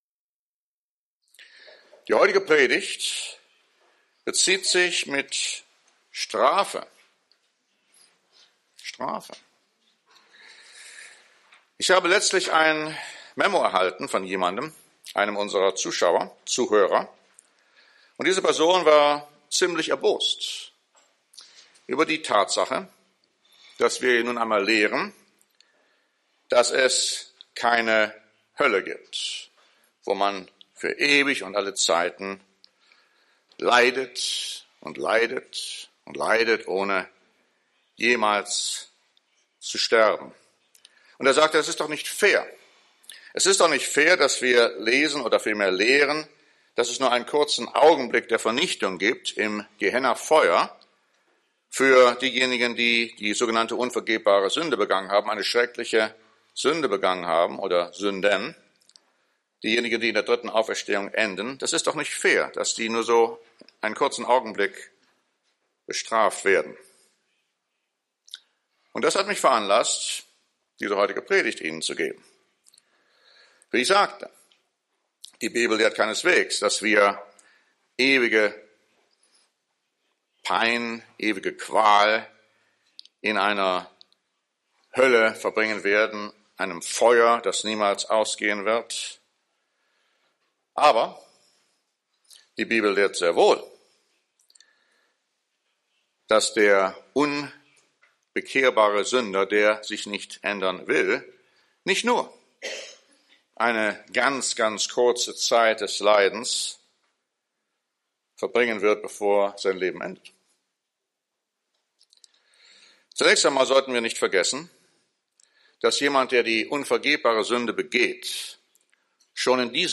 Die Bibel lehrt keine ewig-brennende Hölle, aber das bedeutet nicht, dass die unverbesserlichen Sünder in der dritten Auferstehung vor ihrer endgültigen Vernichtung keine physischen und psychologischen Strafen erleiden müssten. Diese Predigt zeigt, was die Bibel uns insoweit offenbart, und warnt uns eindringlich vor dem Schicksal derer, die die unvergebbare Sünde begehen.